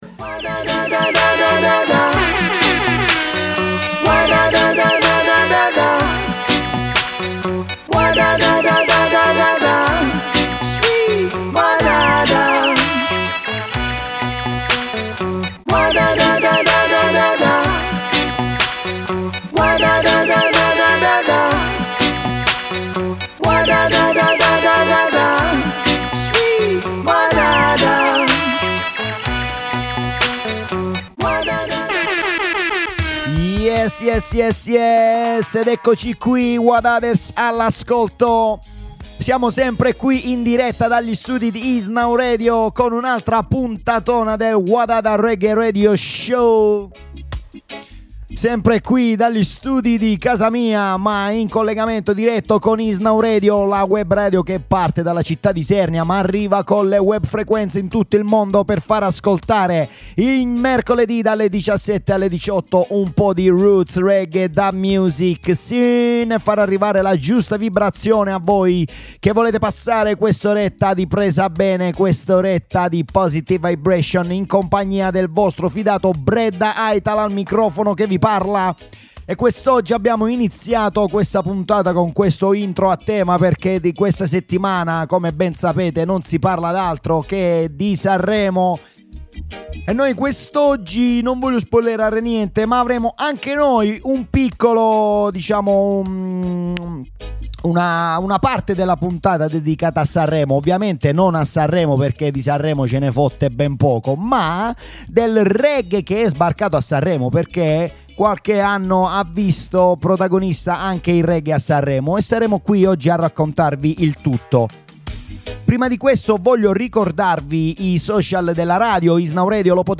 The Best Web Radio Show Of Roots Reggae Dub Music